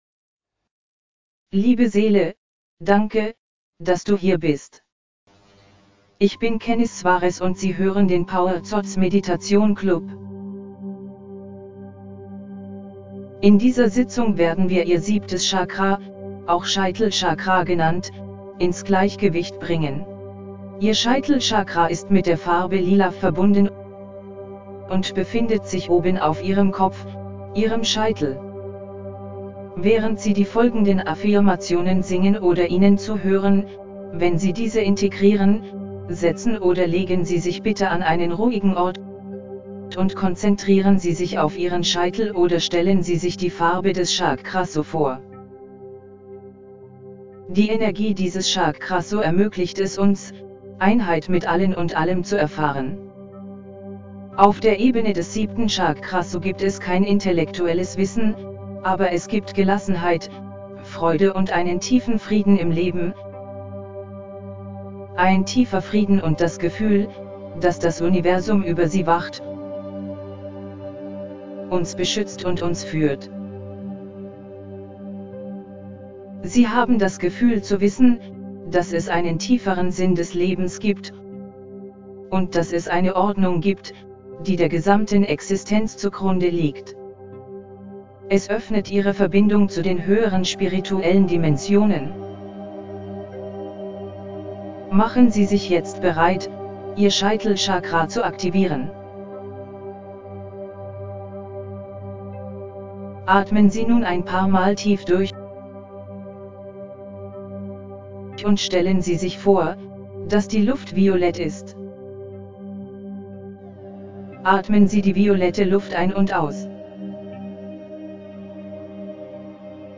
Scheitelchakra ➤ Geführte Meditation
Scheitelchakra ➤ Geführte Meditation Vollständige CHAKRA-Heilung ➤ Mit Solfeggio 963 Hz | Geführte Meditation | Binaurale Beats | Navajo-Trommeln In dieser Sitzung werden wir Ihr siebtes Chakra, auch Kronenchakra genannt, ins Gleichgewicht bringen.